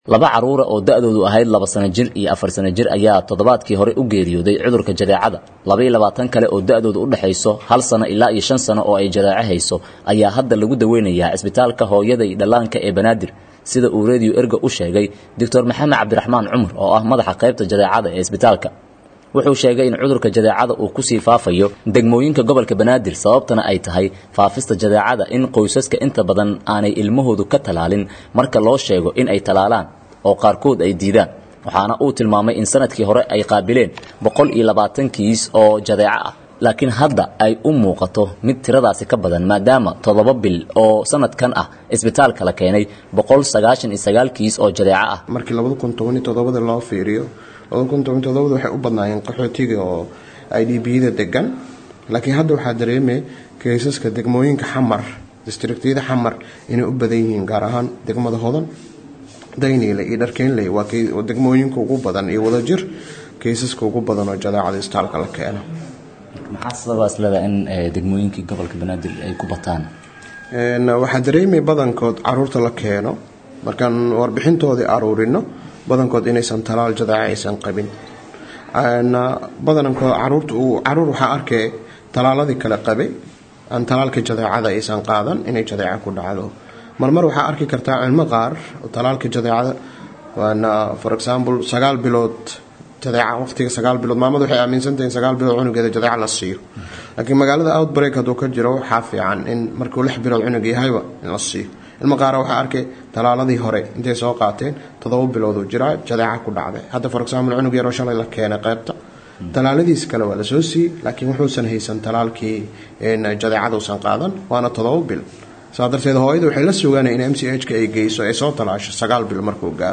Warbixin-ku-saabsan-Cudurka-Jadeecada-Isbitaal-Banaadir-.mp3